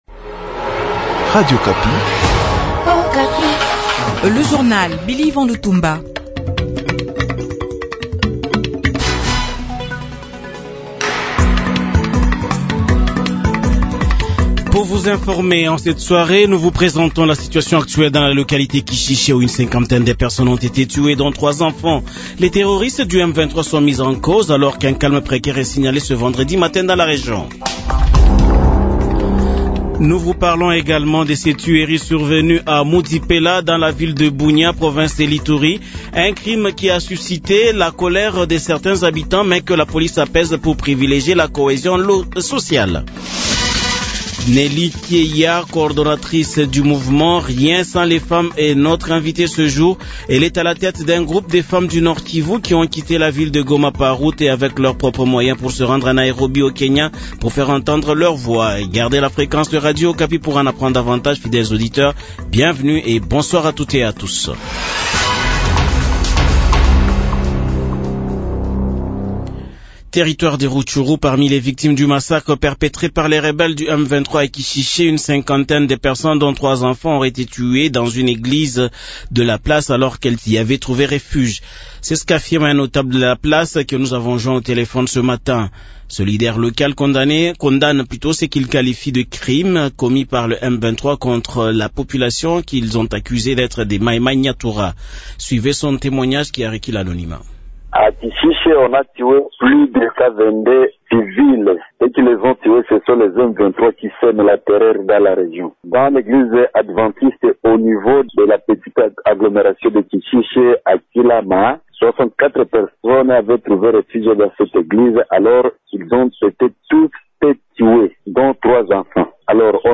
GOMA : Au lendemain du massacre de Kishishie, un habitant témoigne sur ce qui s’est passé